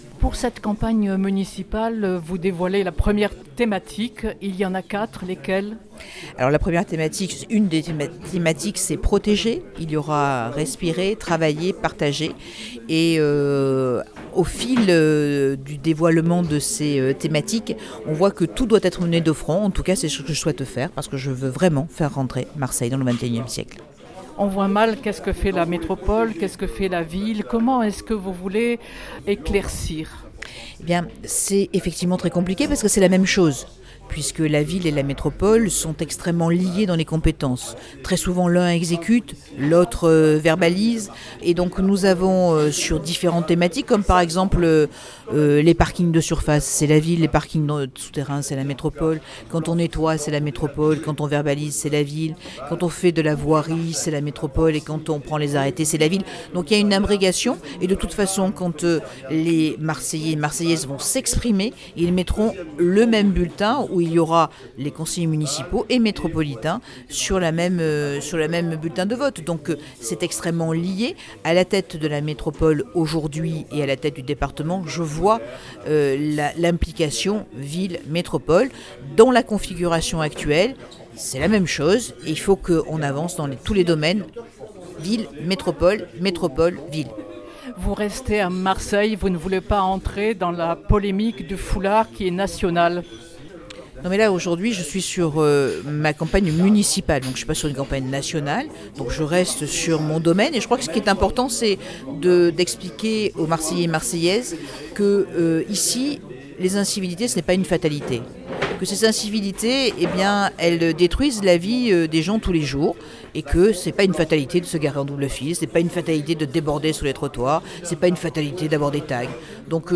son_copie_petit-397.jpgEntretien avec Martine Vassal LR candidate à la mairie de Marseille